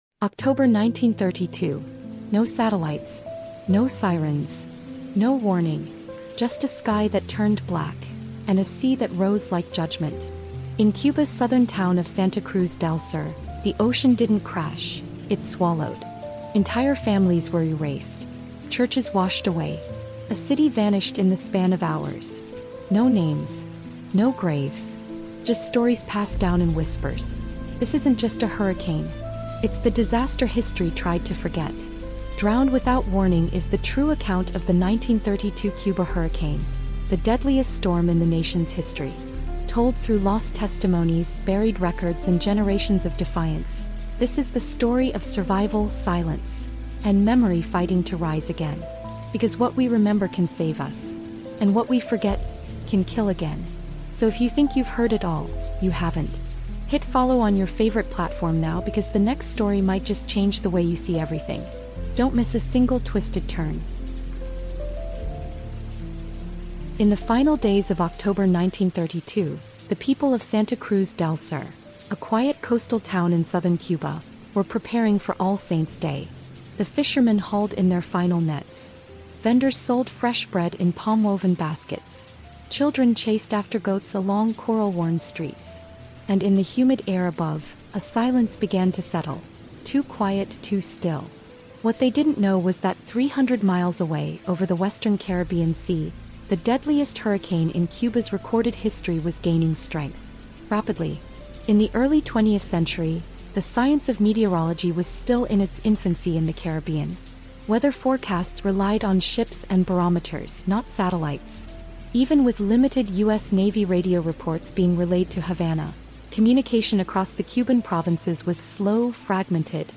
Drowned Without Warning is a powerful, fact-based Caribbean History Audiobook Documentary that resurrects the long-buried truth of the storm that erased entire towns along Cuba’s southern coast. Told through survivor testimonies, hidden government records, and the voices of descendants, this immersive historical story examines how censorship, colonization, racial injustice, and media silence conspired to bury a national tragedy.